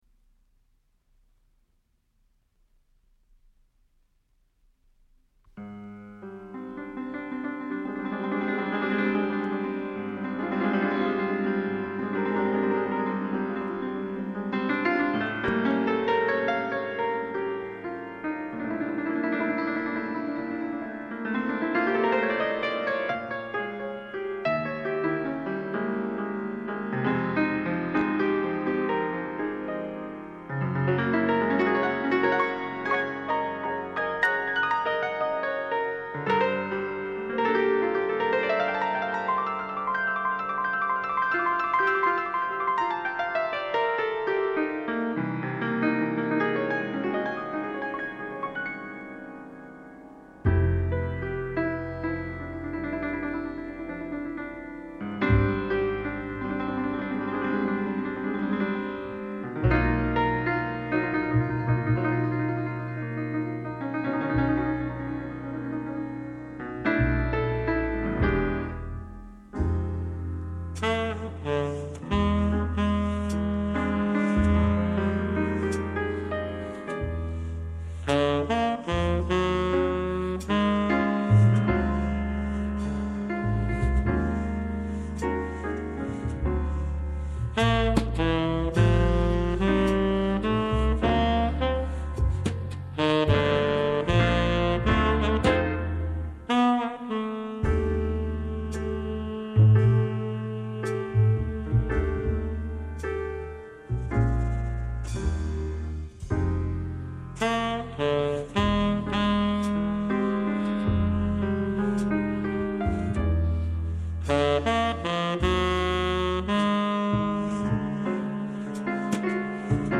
modern jazz